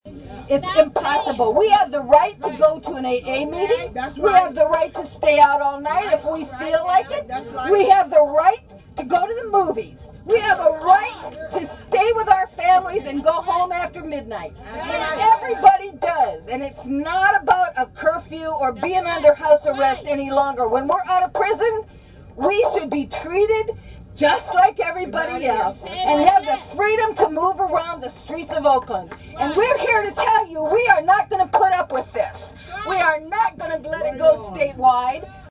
Audio from 1/26 demonstration at Oakland Mayor Jerry Brown's loft apartment protesting his plans to implement a curfew for all future probationers and parolees between 10pm and 6am.